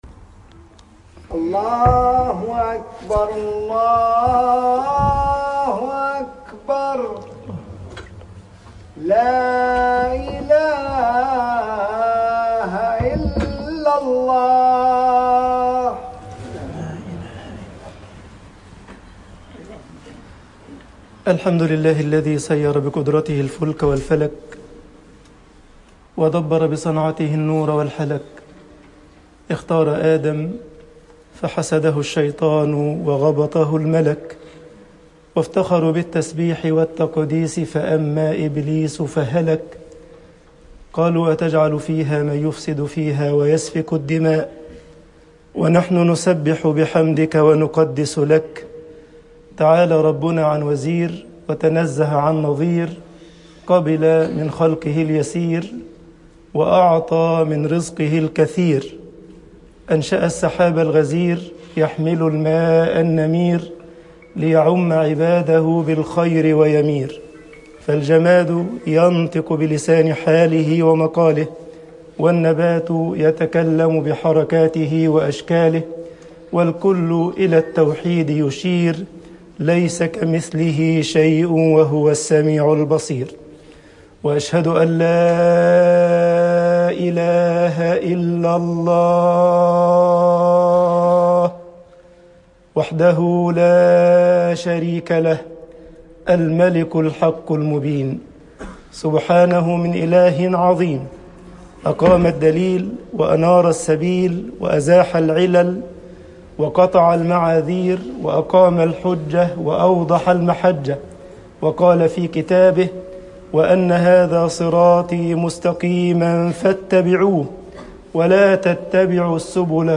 خطب الجمعة والعيد